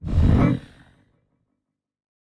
Minecraft Version Minecraft Version snapshot Latest Release | Latest Snapshot snapshot / assets / minecraft / sounds / mob / warden / listening_3.ogg Compare With Compare With Latest Release | Latest Snapshot